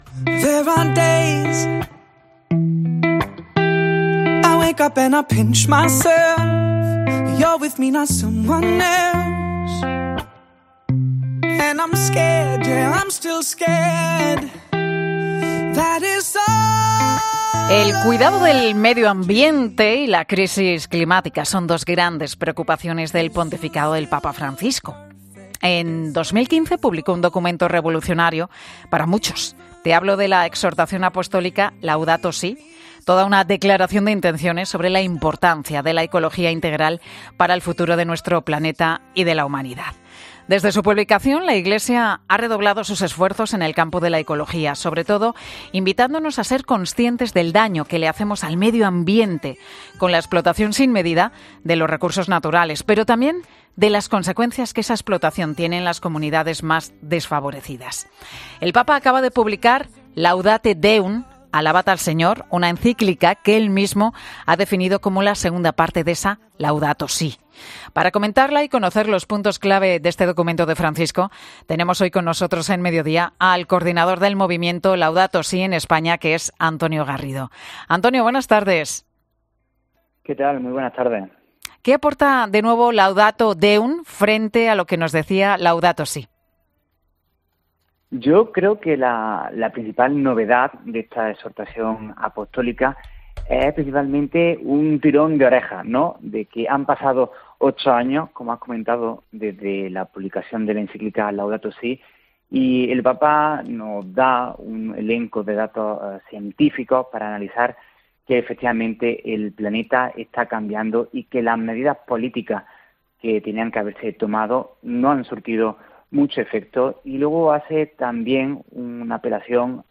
por los micrófonos de 'Mediodía COPE' ha pasado